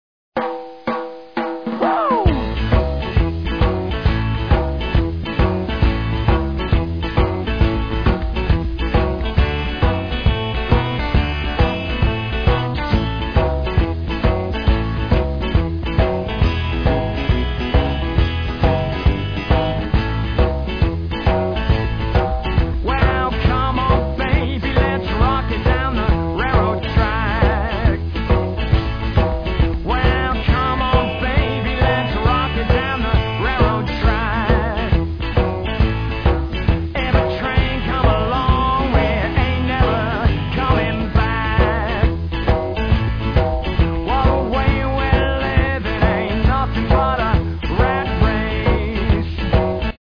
All samples are 8bit 11KHz mono recordings
Rhythm & Blues experience you'll never forget